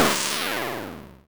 sfx_train_arrive.wav